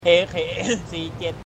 PLAY ak47 shooting sound
ak47_M8UNB0T.mp3